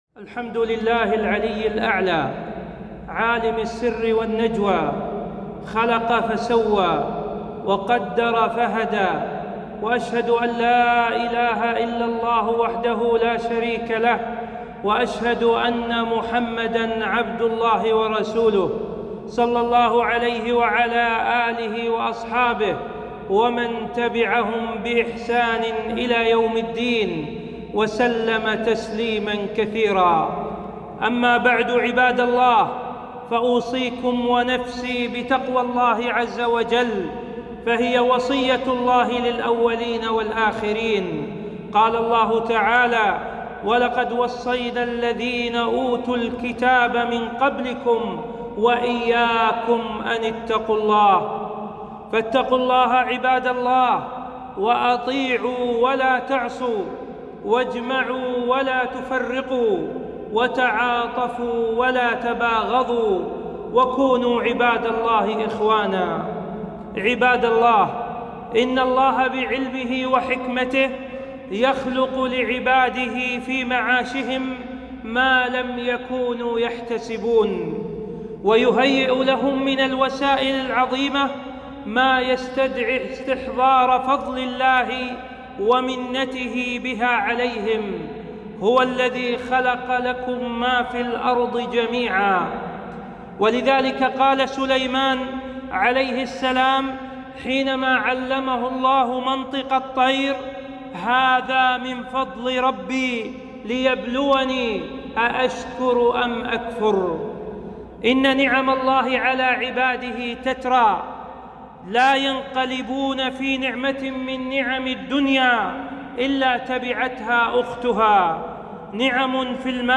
خطبة - خطورة الحسابات الوهمية في مواقع التواصل الاجتماعي